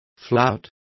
Complete with pronunciation of the translation of flouting.